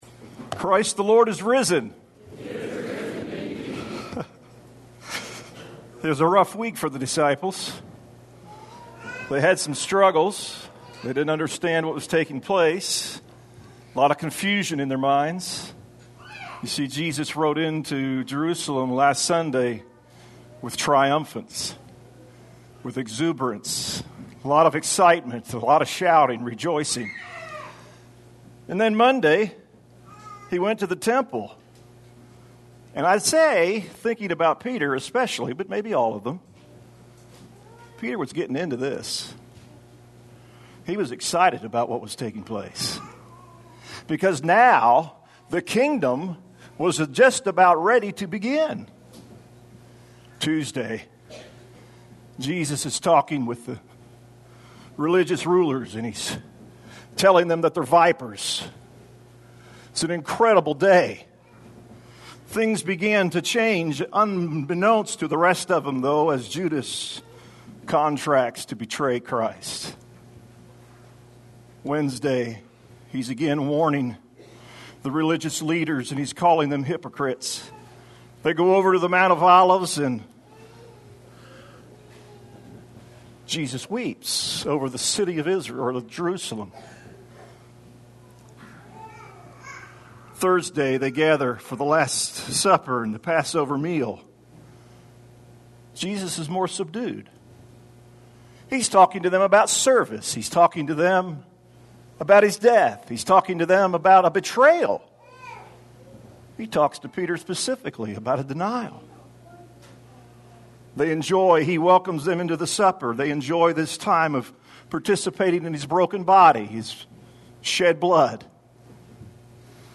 Easter Service (Cantata)
Easter Sunday morning service featuring a cantata